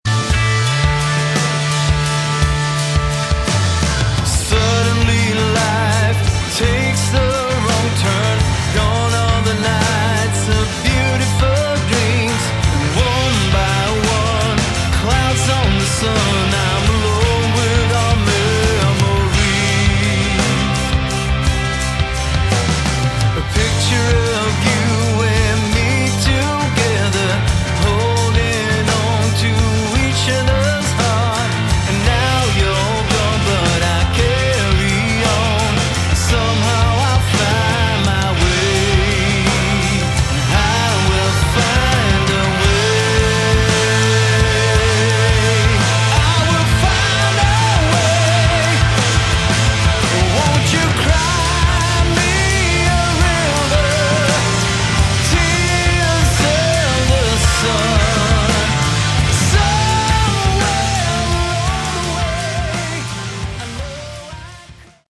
Category: Melodic Hard Rock
lead & backing vocals
lead & rhythm guitar, backing vocals
bass, backing vocals
drums, percussion, backing vocals
organ, keyboards, backing vocals